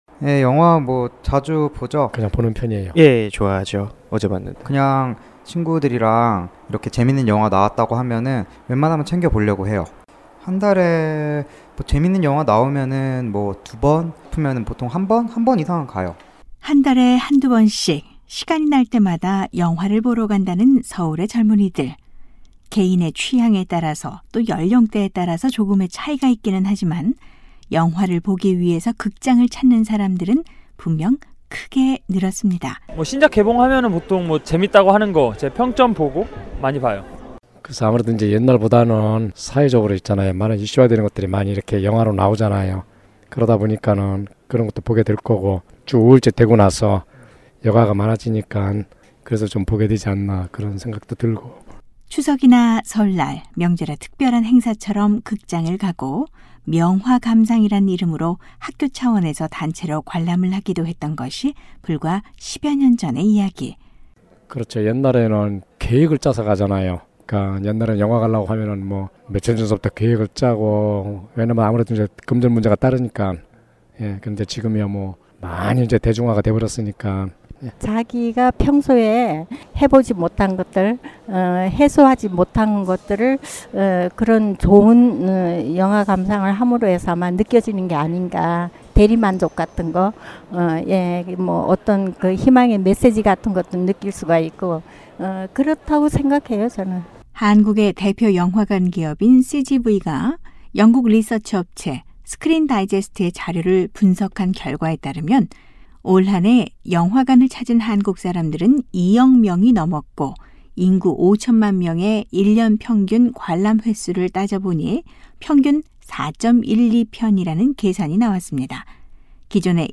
올 한해 한국 사람들이 가장 좋아했던 영화가 무엇인지, 또 영화관을 찾는 이유가 무엇인지 서울 시민들의 목소리를 담아봤습니다.